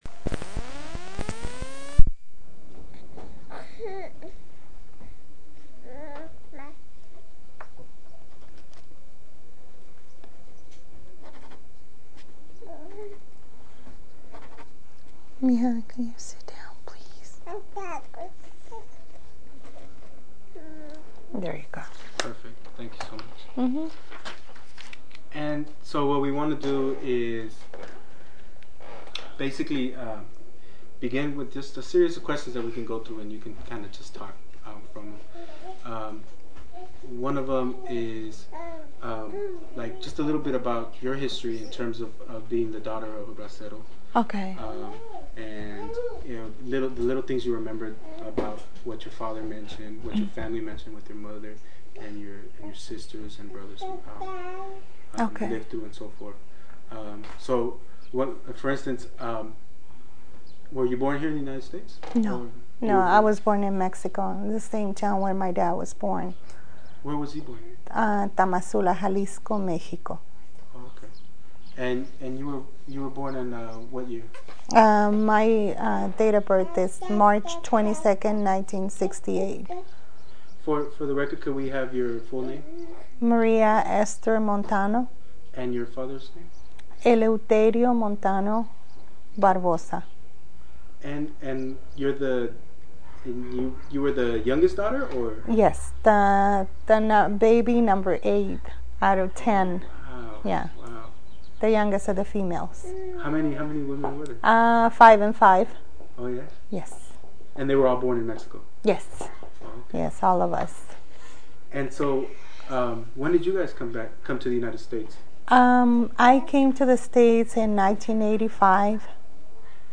Location Los Angeles, California Original Format Mini Disc